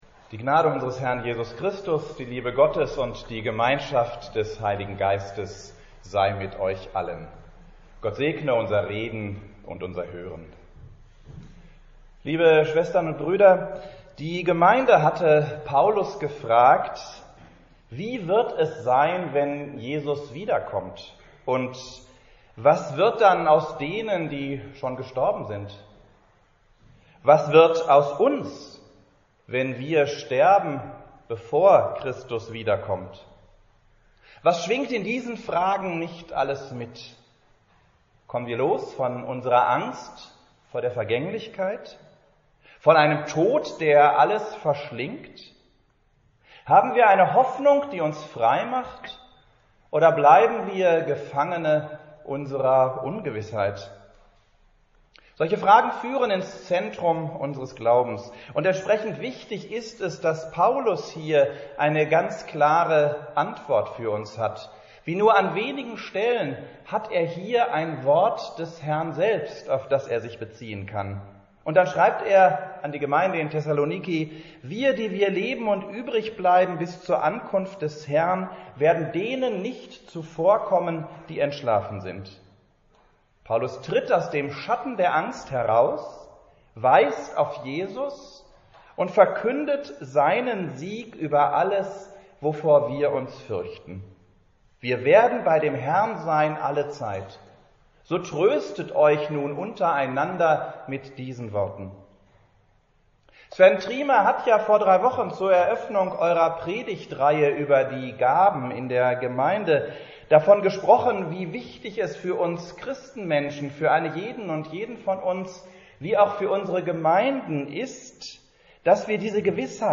Predigt des Superintendenten am 08.11.2020 zu 1. Thessalonicher 5,1-11